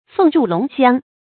凤翥龙骧 fèng zhù lóng xiāng 成语解释 形容奋发有为。